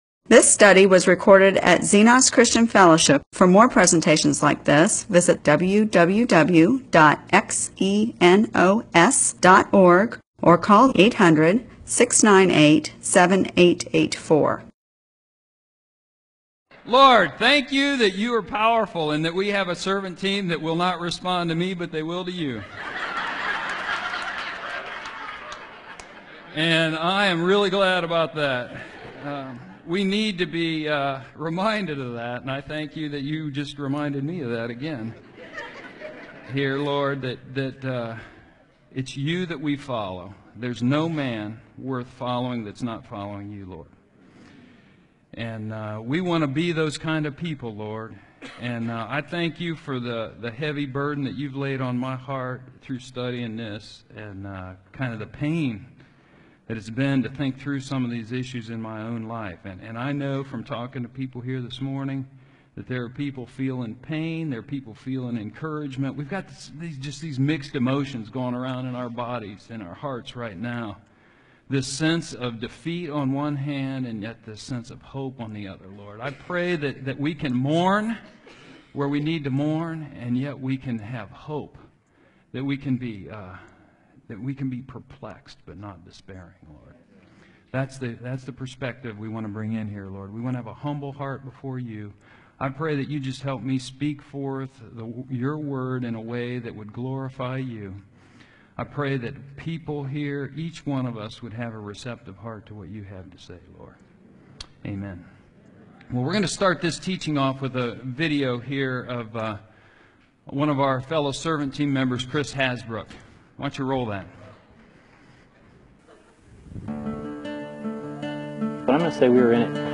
MP4/M4A audio recording of a Bible teaching/sermon/presentation about .